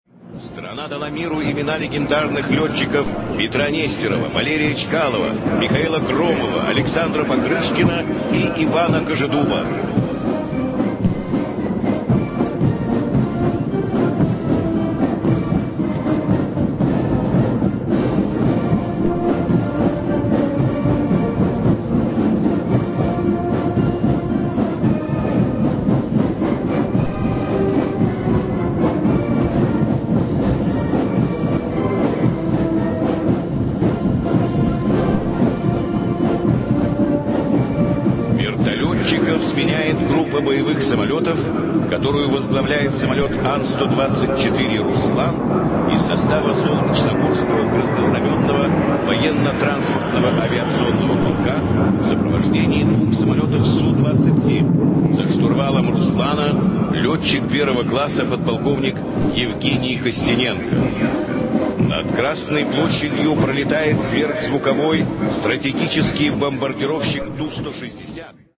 Вначале вспомним недавний парад на Красной площади столицы, который состоялся 9 мая 2008 года.
Из динамиков доносятся бодрые звуки «Авиамарша» — давнишнего официального марша военно-воздушных сил страны: